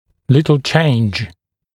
[‘lɪtl ʧeɪnʤ][‘литл чэйндж]небольшие изменения